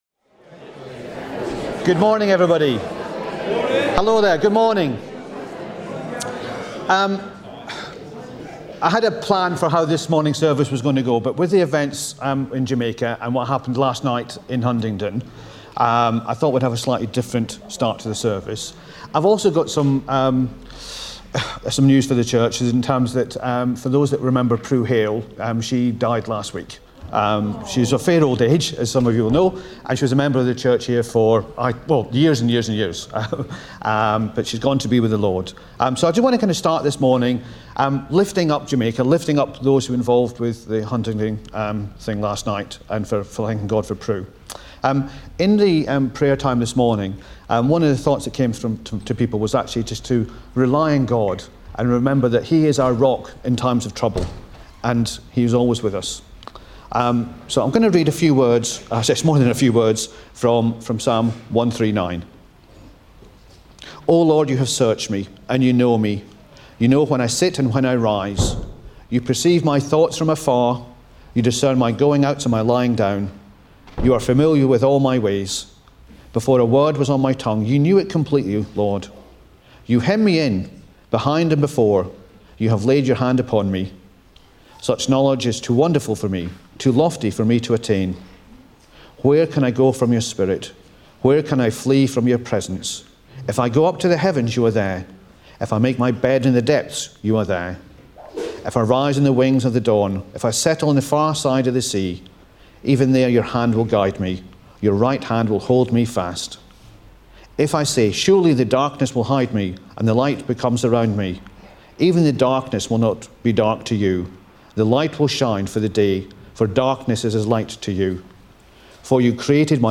2 November 2025 – Morning Service
Service Type: Morning Service